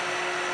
grind.ogg